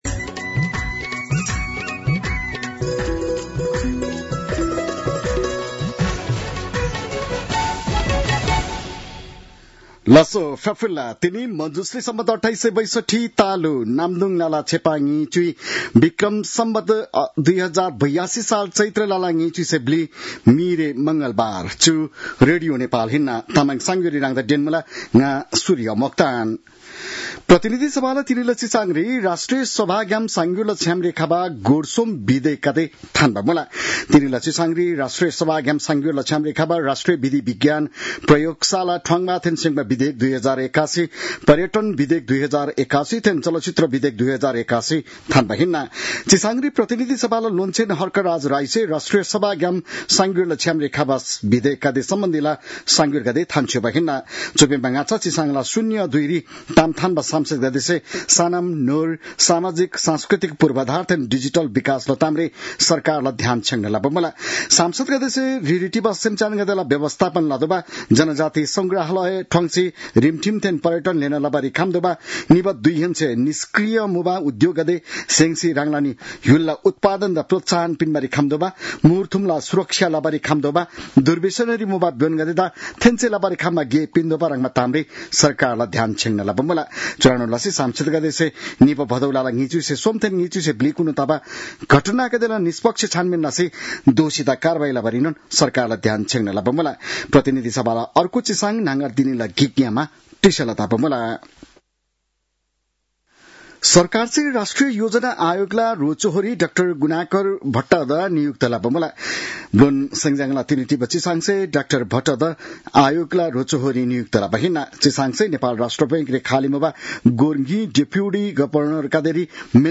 तामाङ भाषाको समाचार : २४ चैत , २०८२